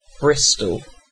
Ääntäminen
UK RP : IPA : /ˈbɹɪstəl/